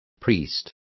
Also find out how sacerdote is pronounced correctly.